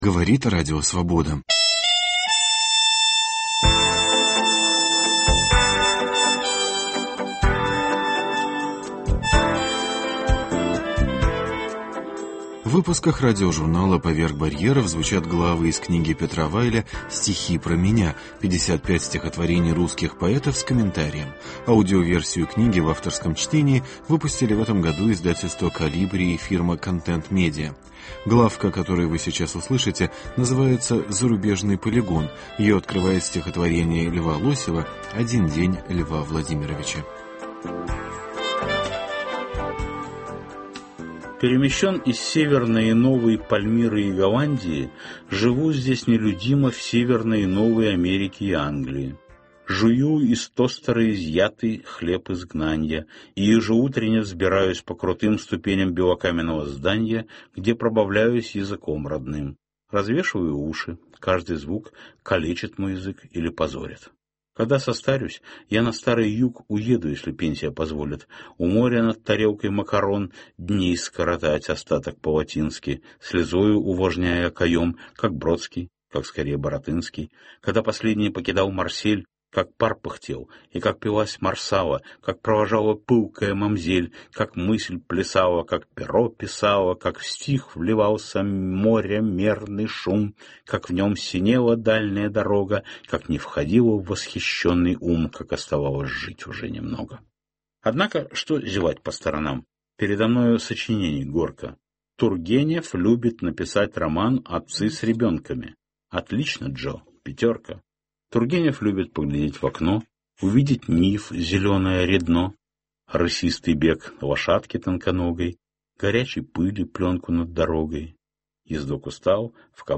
Петр Вайль читает главу из своей книги «Стихи про меня».